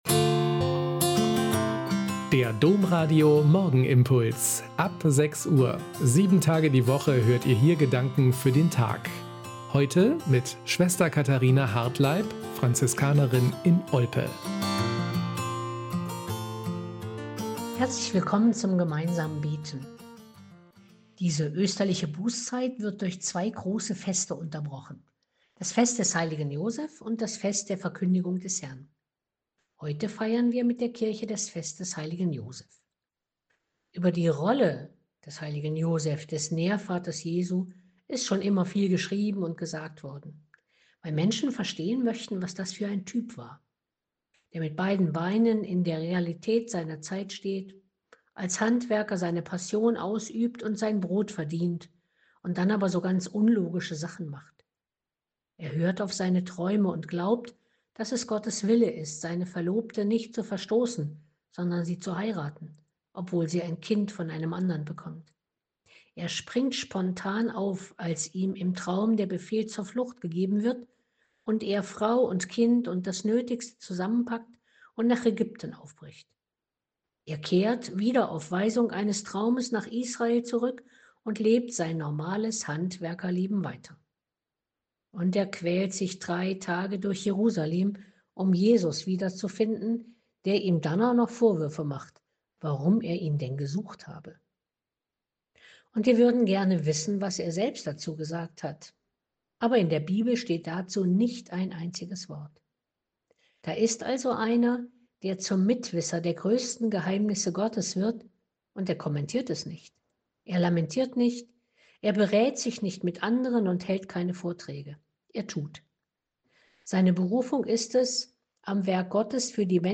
Morgenimpuls